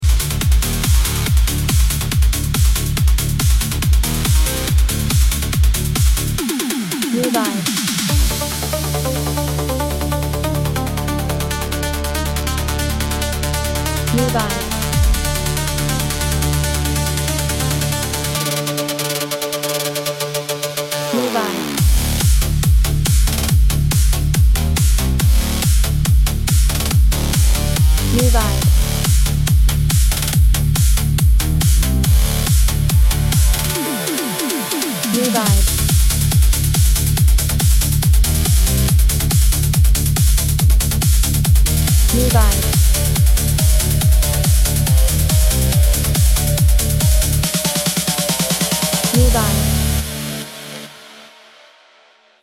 Genre: EDM (heavy beat)